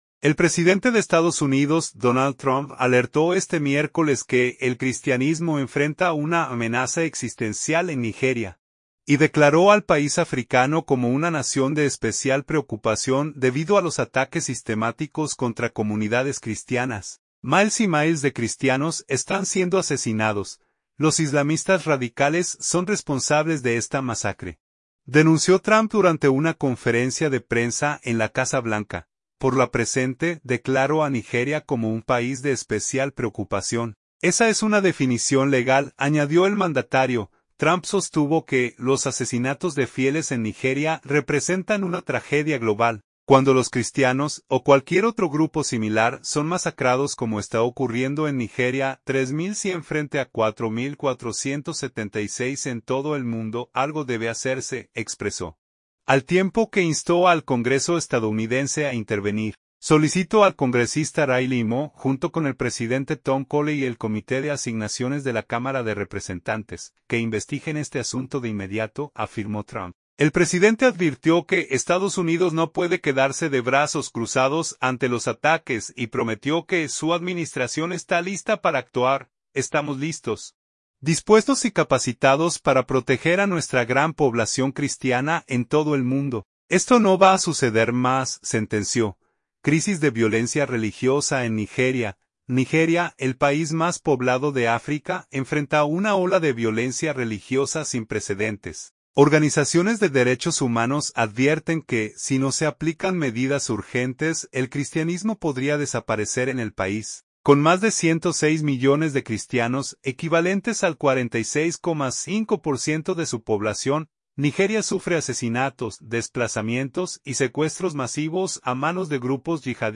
Los islamistas radicales son responsables de esta masacre”, denunció Trump durante una conferencia de prensa en la Casa Blanca.